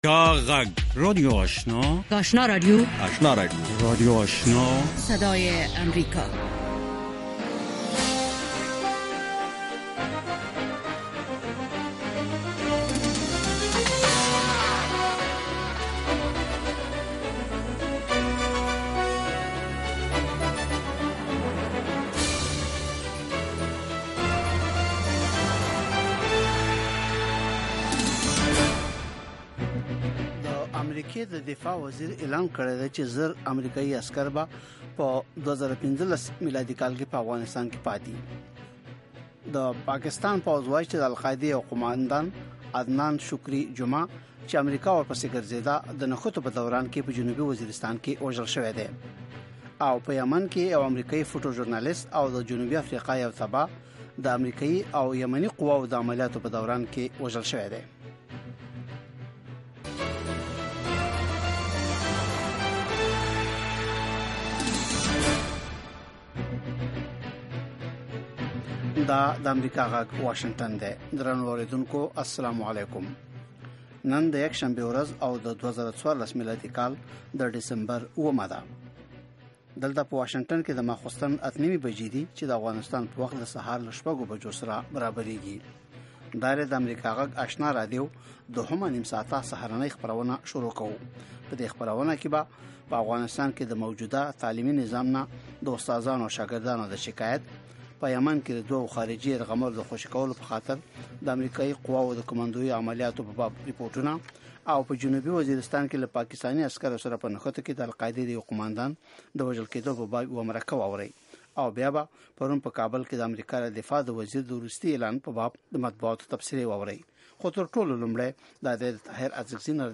دویمه سهارنۍ خبري خپرونه
په دې نیم ساعته خپرونه کې د افغانستان او نورې نړۍ له تازه خبرونو وروسته مهم رپوټونه او مرکې اورېدای شئ.